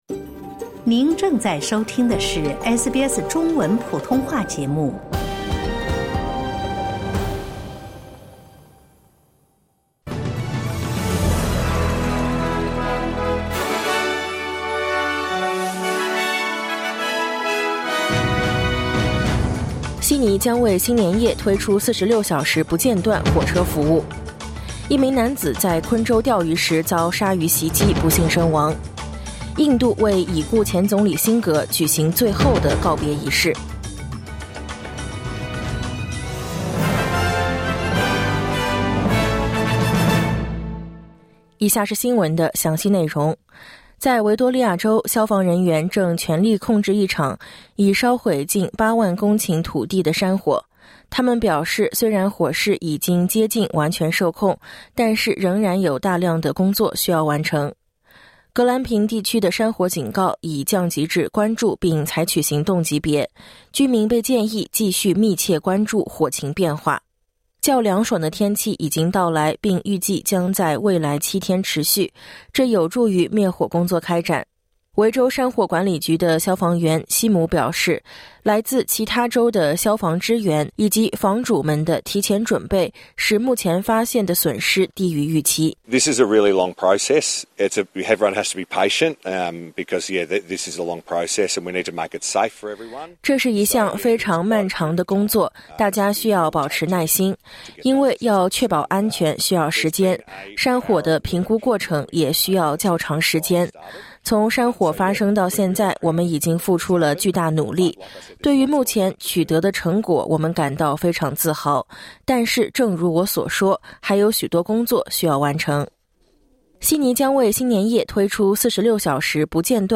SBS早新闻（2024年12月29日）